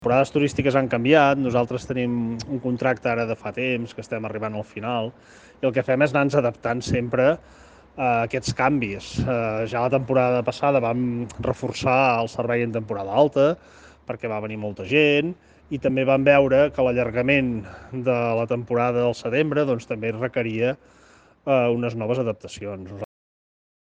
Marc Calvet, primer tinent d’alcalde i responsable de la Gestió de contractes de serveis municipals, ha explicat a Ràdio Capital que treballen “permanentment” en aquest servei i que “en cap cas es pot parlar d’abandonament”.